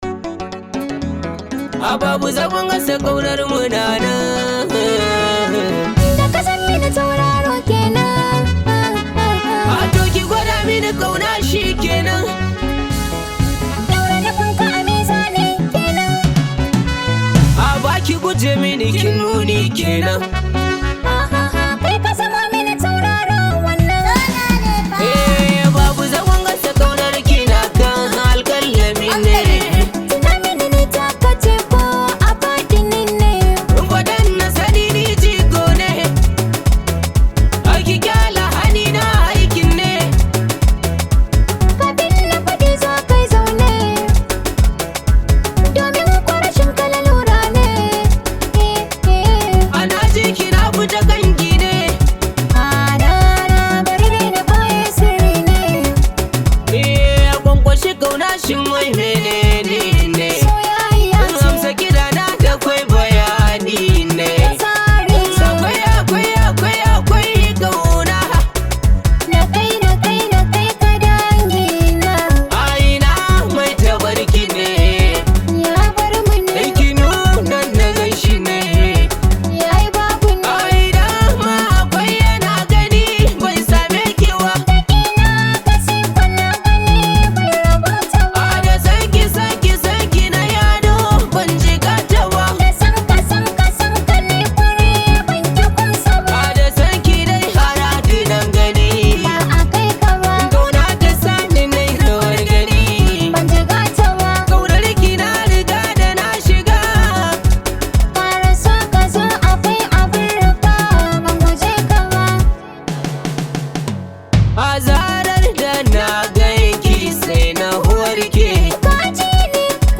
top rated Nigerian Hausa Music artist
This high vibe hausa song